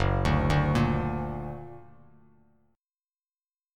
Listen to Gb+ strummed